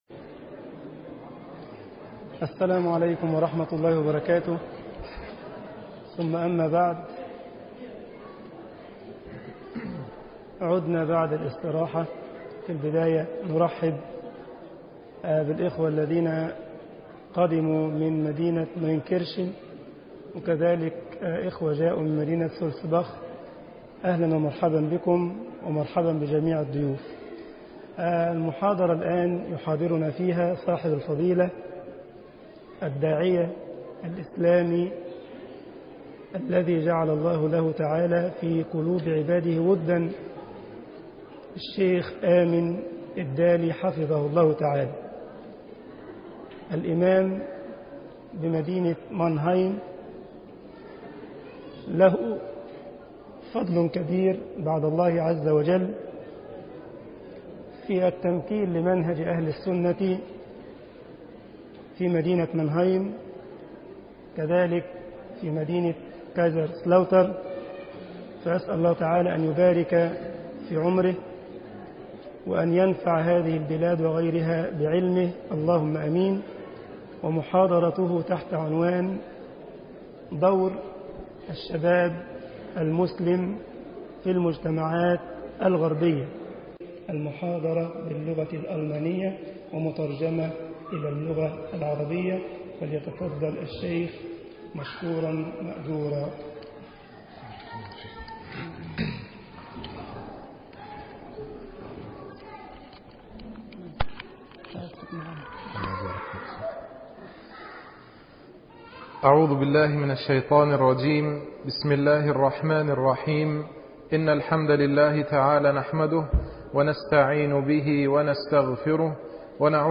المؤتمر الإسلامي الأول: المنهج التربوي في الإسلام
STE-000_Konferenz-Die Rolle der muslimischen Jugend in den westlichen Gesellschaften.mp3